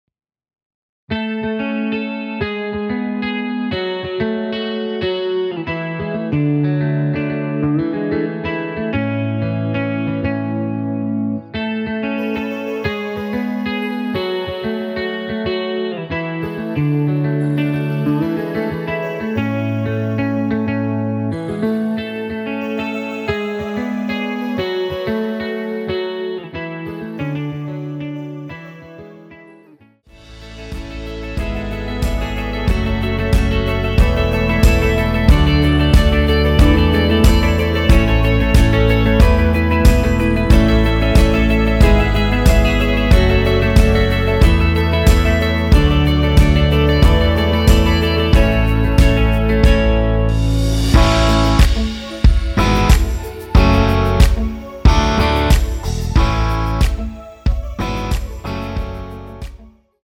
원키(2절 삭제)하고 진행 되는 멜로디 포함된 MR입니다.(미리듣기 확인)
앞부분30초, 뒷부분30초씩 편집해서 올려 드리고 있습니다.
중간에 음이 끈어지고 다시 나오는 이유는